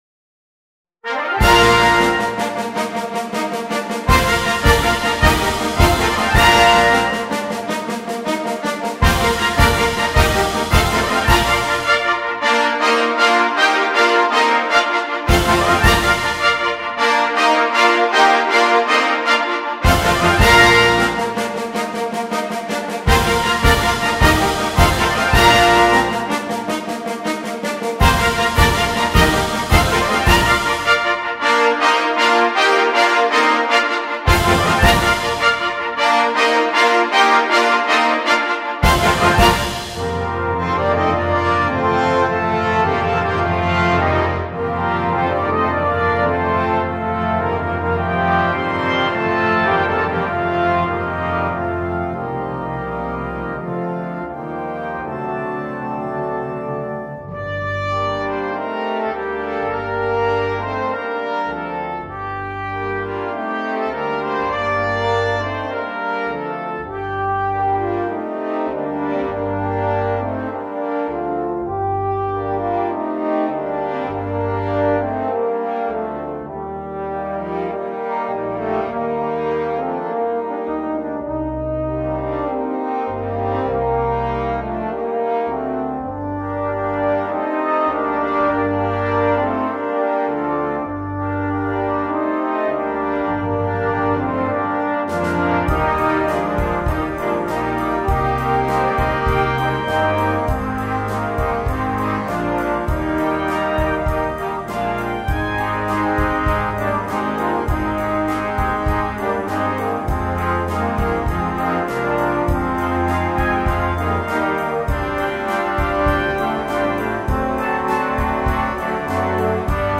2. Brass Band
Full Band
without solo instrument
Entertainment